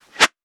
weapon_bullet_flyby_25.wav